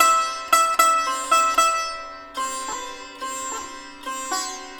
100-SITAR4-R.wav